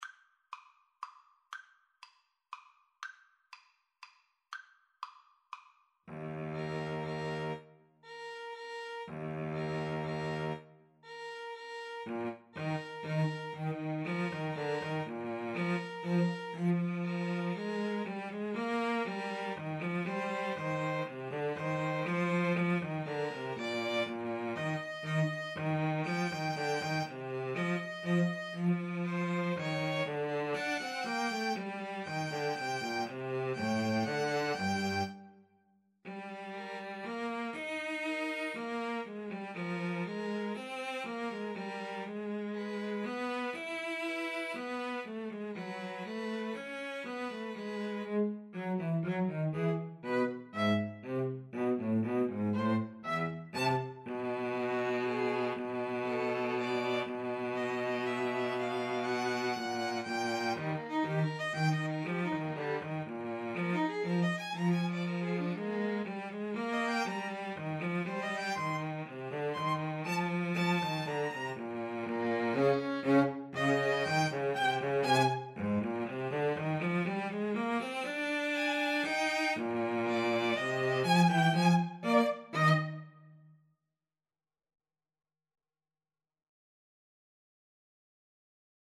Eb major (Sounding Pitch) (View more Eb major Music for 2-Violins-Cello )
Allegretto pomposo = c.120
2-Violins-Cello  (View more Intermediate 2-Violins-Cello Music)